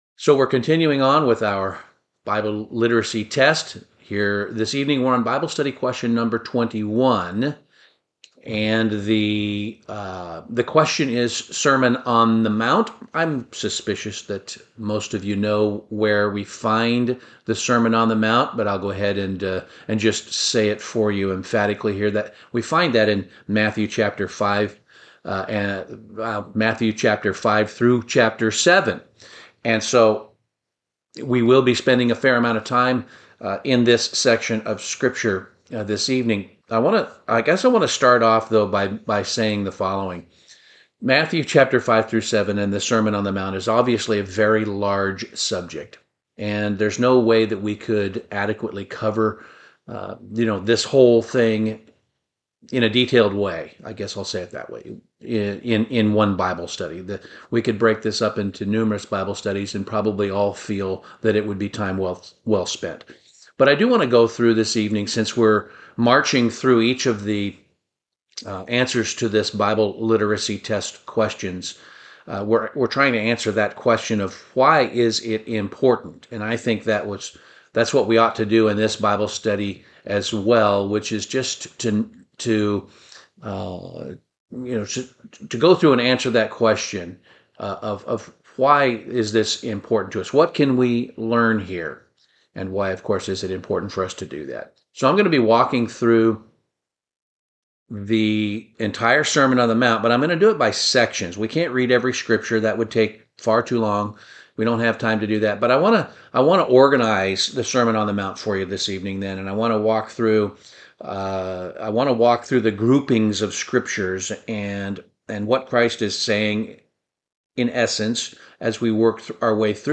Bible Study - The Sermon on the Mount